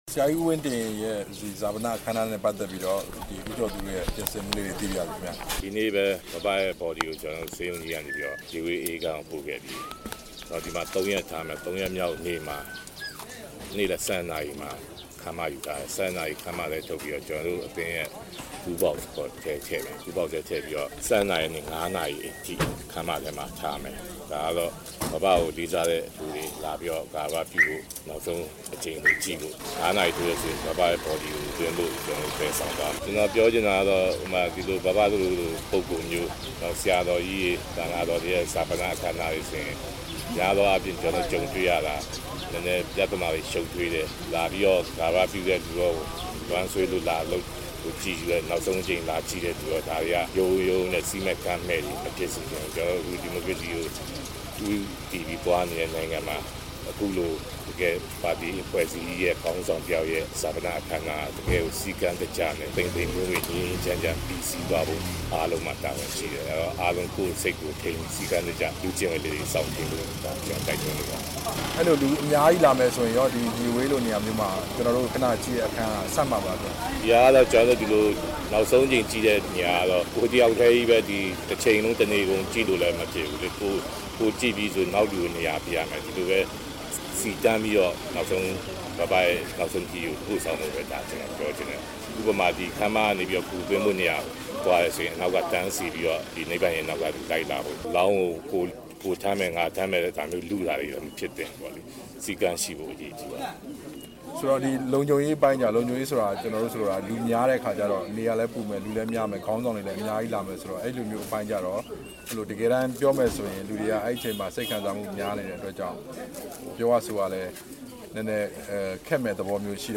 နာရေးကူညီမှုအသင်း (ရန်ကုန်) ဥက္ကဋ္ဌ ဦးကျော်သူနဲ့ တွေ့ဆုံ မေးမြန်းချက်